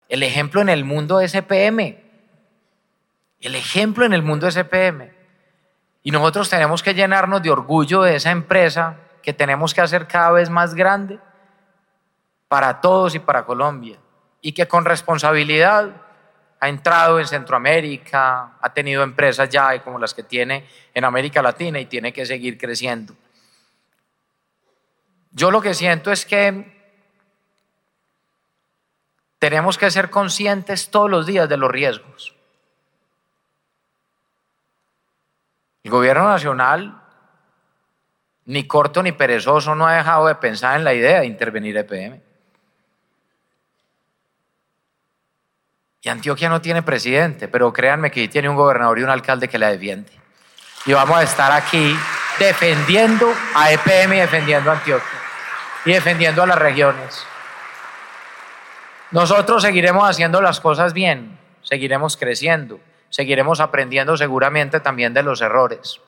Durante la celebración de los 70 años de EPM, el alcalde Federico Gutiérrez reafirmó que la empresa seguirá siendo 100 % pública.
Declaraciones-del-alcalde-de-Medellin-Federico-Gutierrez-Zuluaga.-EPM.mp3